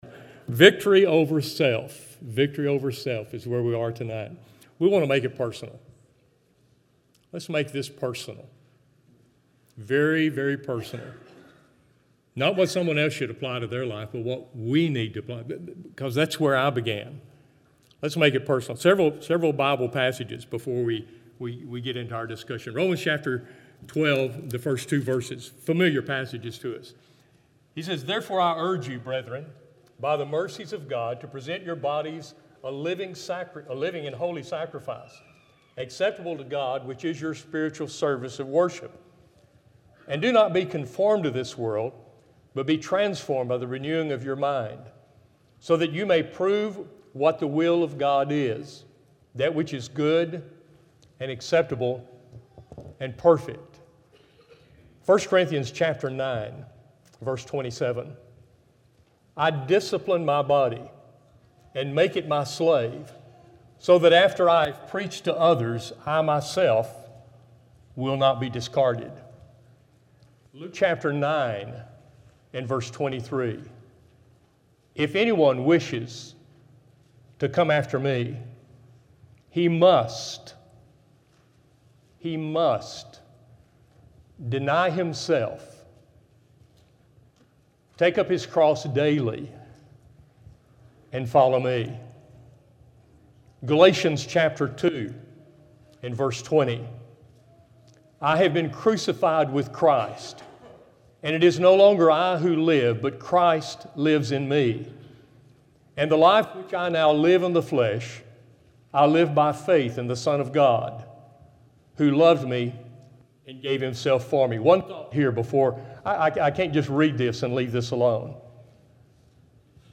Audio Sermons Lectureship - 2017